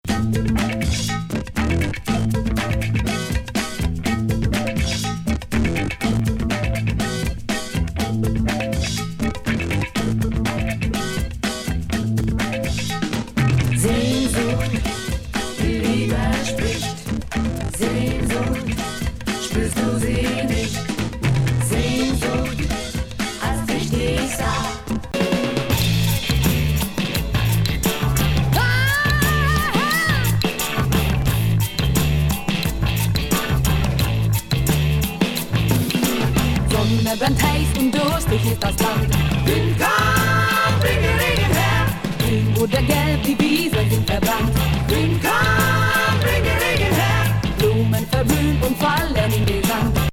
ファジーなギター&ベース・ブンブンなファンキー・ロック「WIND,